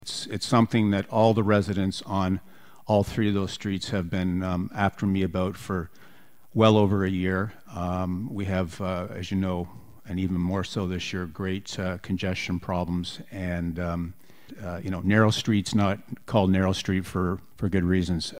Councillor Mike Harper said he thinks the changes would do a lot to alleviate some congestion and parking issues in downtown Wellington.